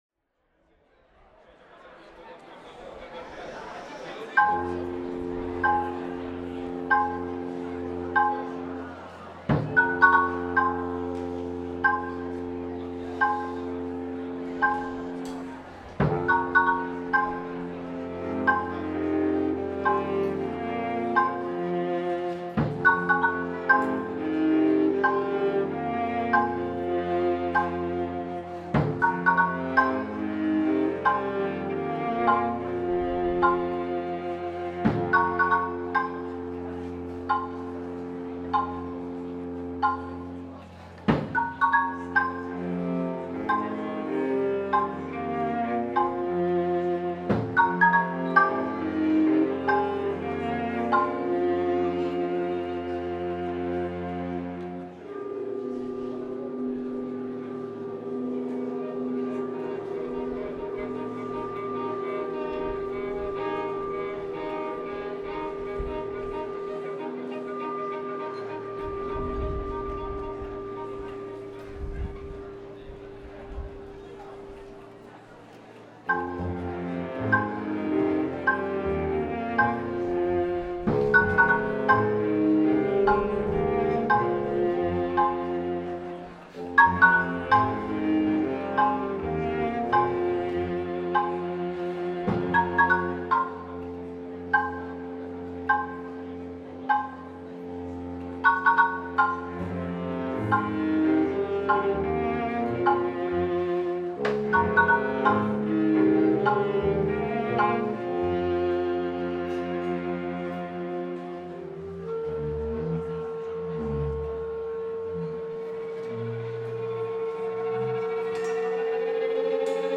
è una composizione per ensemble